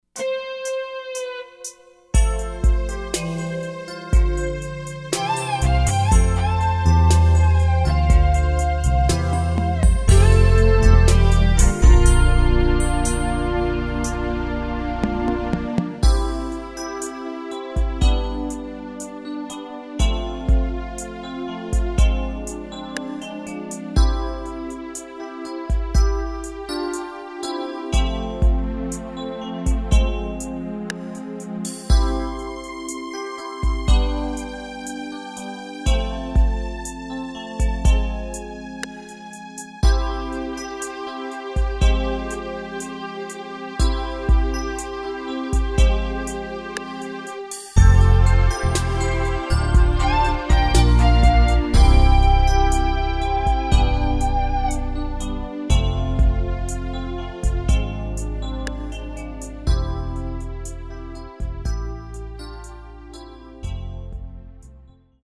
Key of C
Performance quality audio.